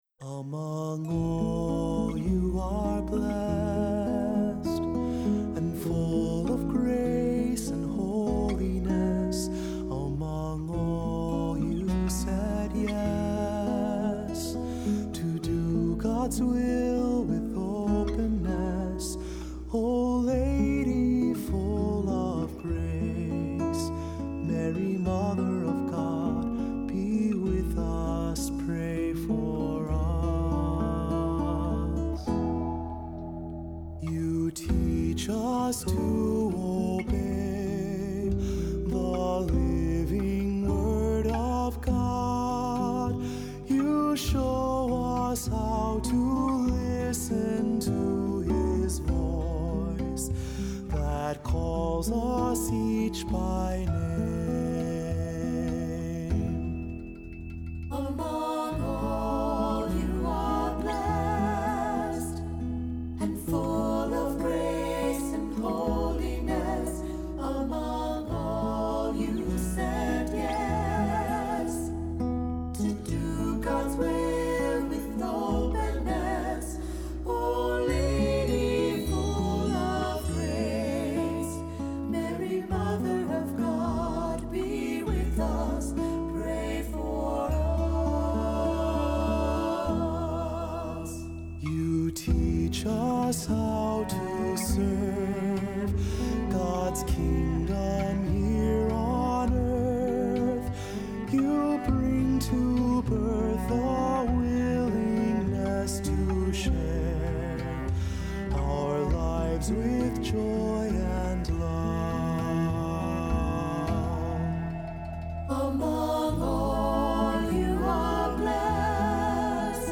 Accompaniment:      Piano
Music Category:      Christian
A hymn addressed to Mary in a contemporary style.